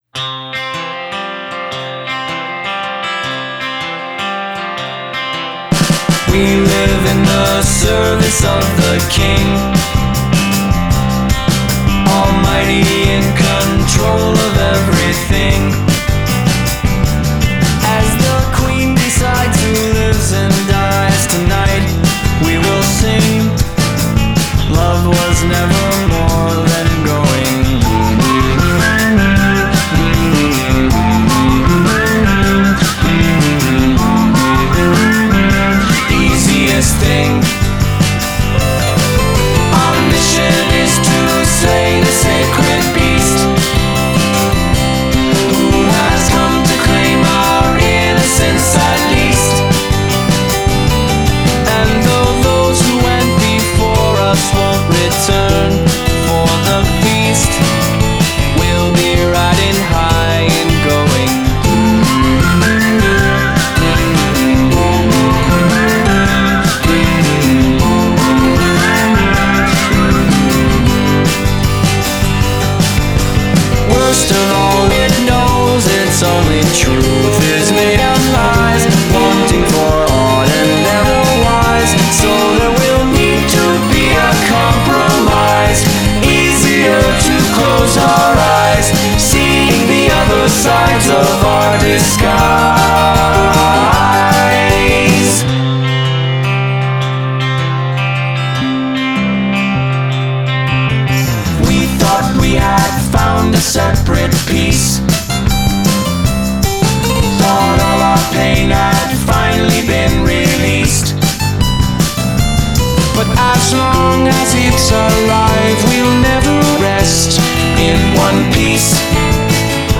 So ‘out there’ but still so good melodically.
features more of the same quirkiness in songs like “&
1960s-ish